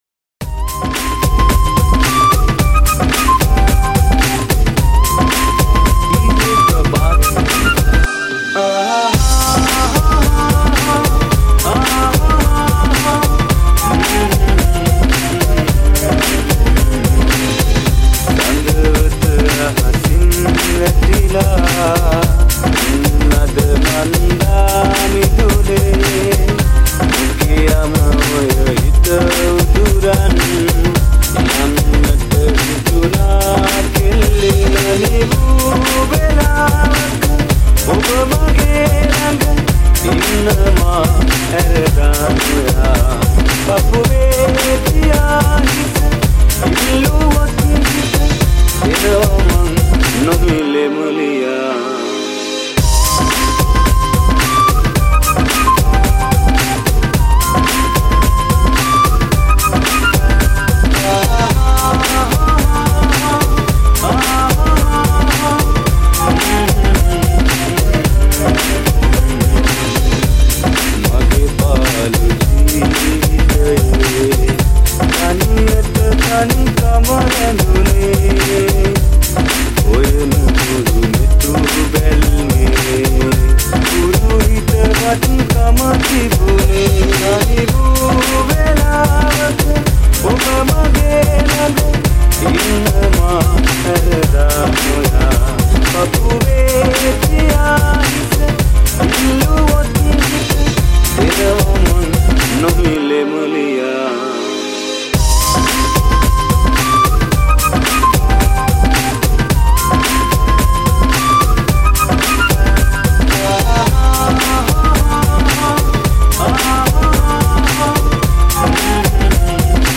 High quality Sri Lankan remix MP3 (3.5).